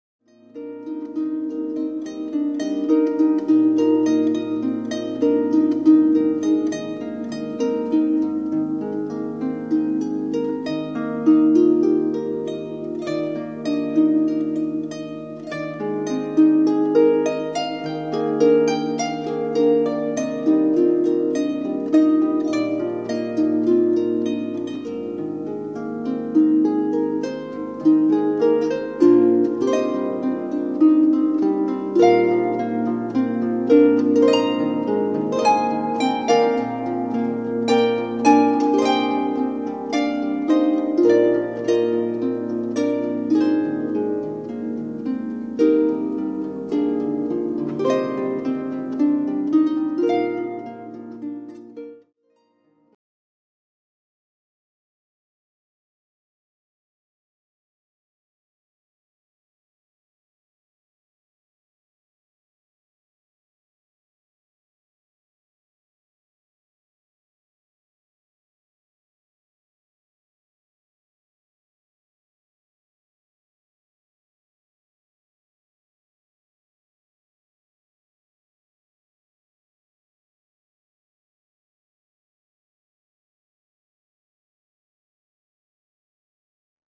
Wedding Harpist